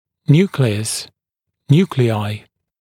[‘njuːklɪəs] мн. [‘njuːklɪaɪ][‘нйу:клиэс] мн. [‘нйу:клиай]ядро (клетки)